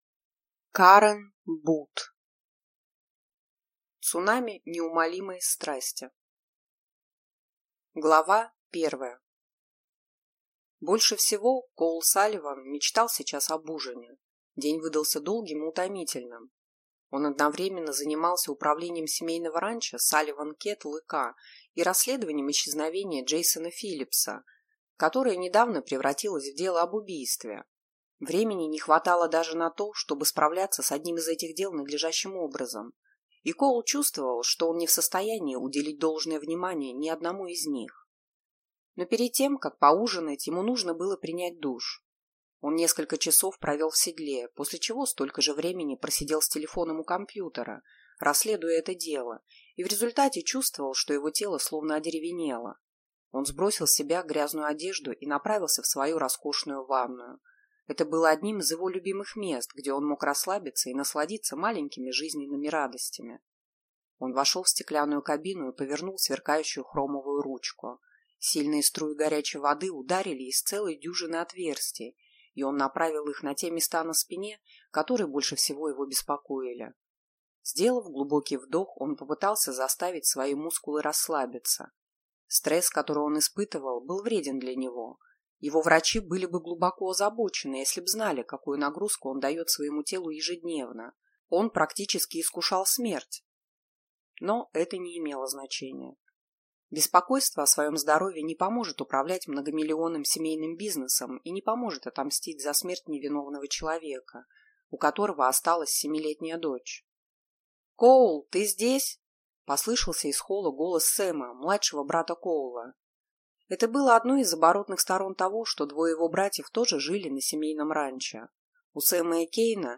Аудиокнига Цунами неумолимой страсти | Библиотека аудиокниг